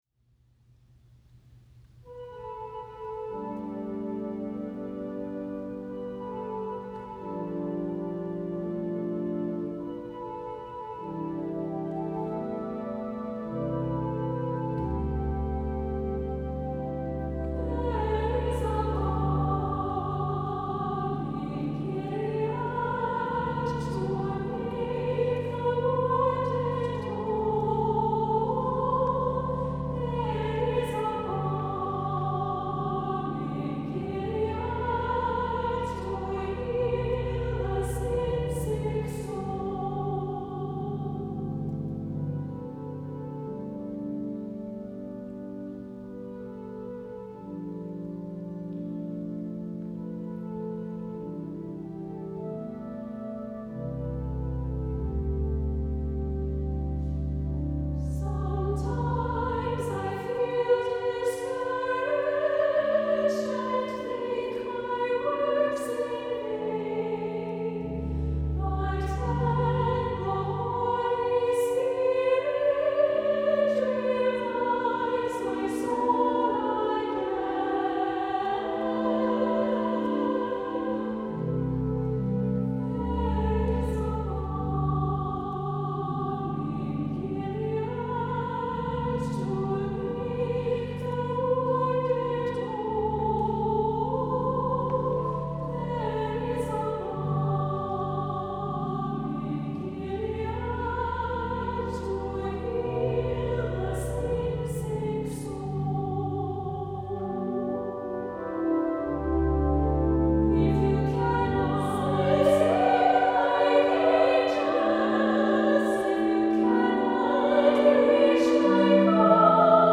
• Music Type: Choral
• Voicing: 2-Part Choir, Treble Choir
• Accompaniment: Organ